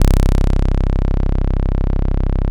24SYN.BASS.wav